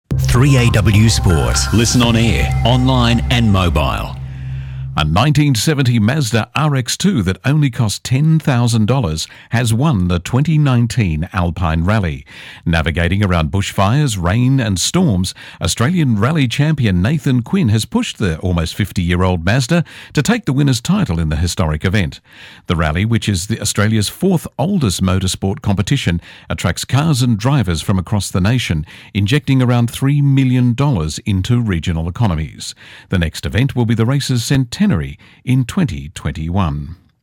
3AW NEWS REPORT